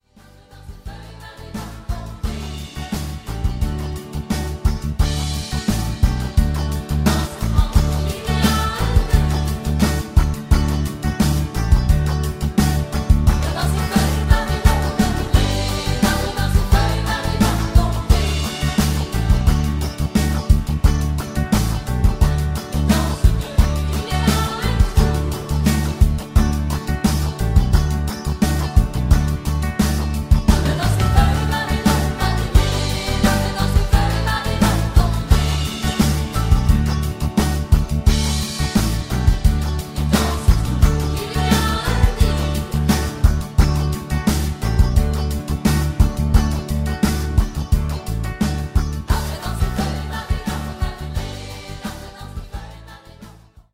avec choeurs